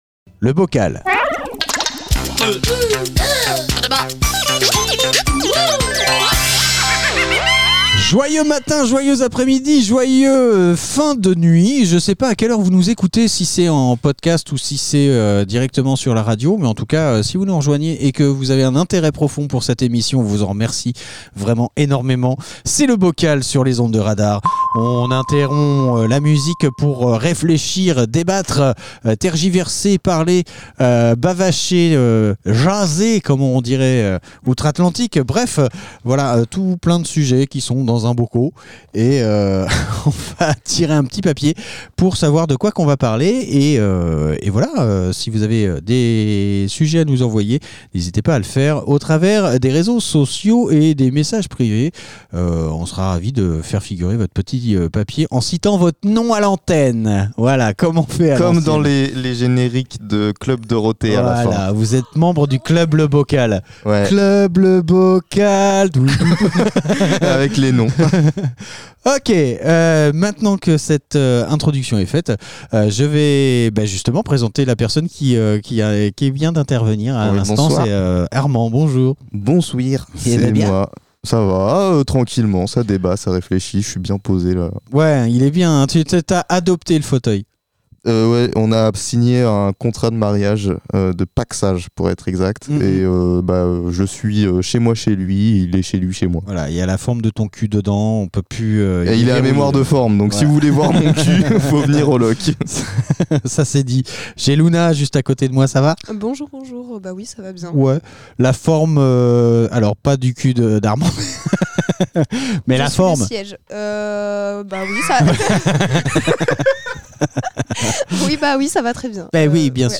Une poignée d'invités propose des sujets de débats à bulletin secret.
Le sujet, une fois dévoilé, donne lieu à des conversations parfois profondes, parfois légères, toujours dans la bonne humeur !